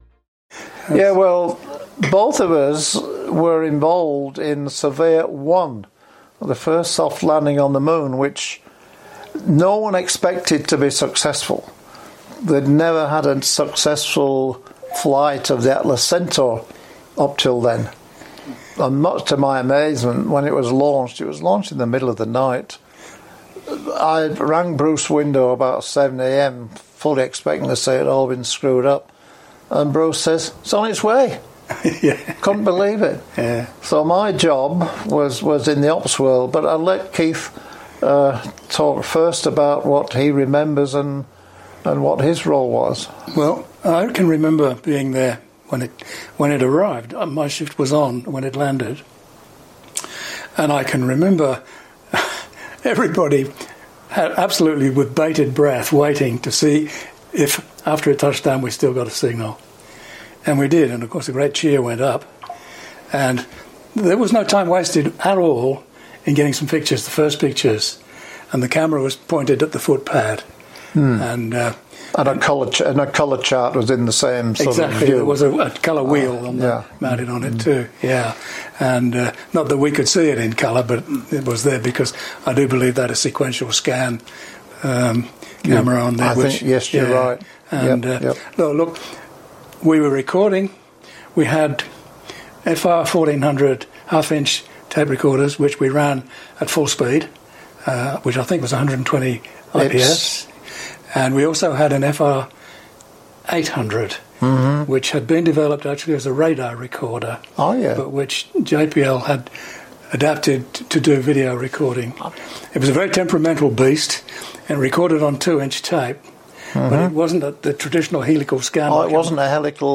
Recorded in Canberra in December 2023, they remember the Surveyor Program which they both supported at Tidbinbilla.